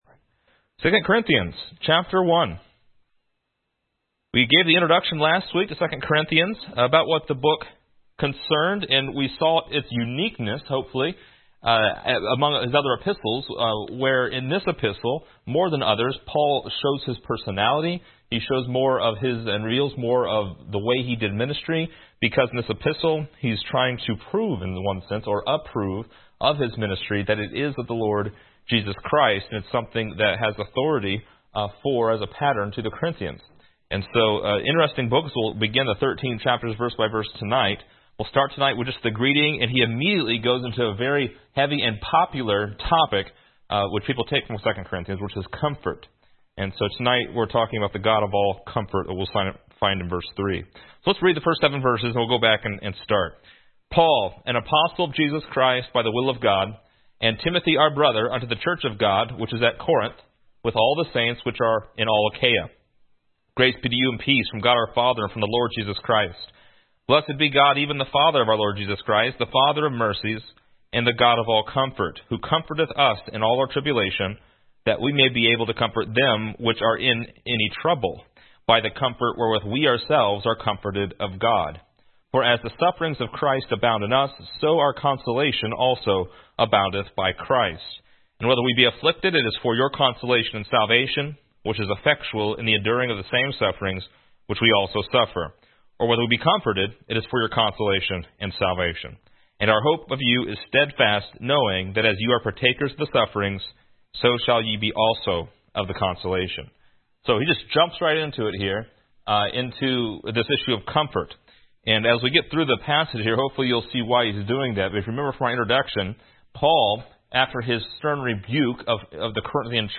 Description: This lesson is part 2 in a verse by verse study through 2 Corinthians titled: God of All Comfort.